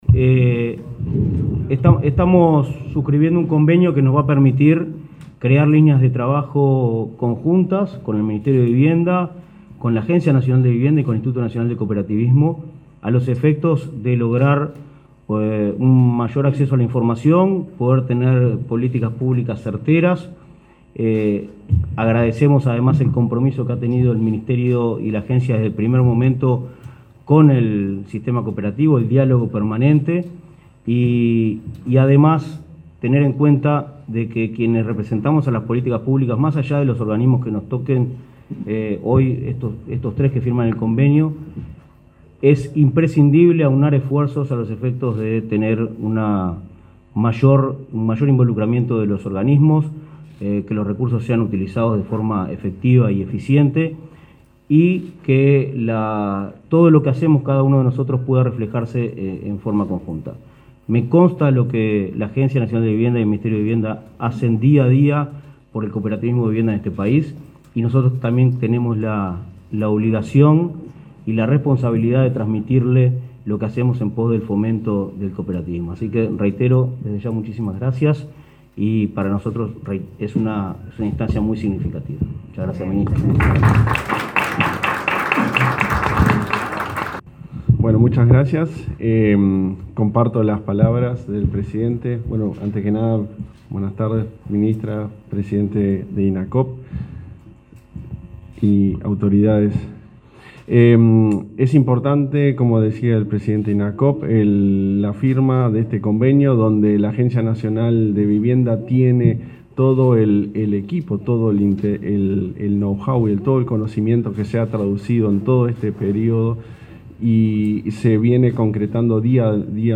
Conferencia de prensa por el convenio para fortalecer el cooperativismo de vivienda
Autoridades del Ministerio de Vivienda y Ordenamiento Territorial (MVOT), la Agencia Nacional de Vivienda (ANV) y el Instituto Nacional del Cooperativismo (Inacoop) firmaron un acuerdo, este 25 de octubre, para fortalecer el cooperativismo de vivienda. Participaron la ministra de Vivienda, Irene Moreira; el presidente de la ANV, Klaus Mill, y el presidente de Inacoop, Martín Fernández.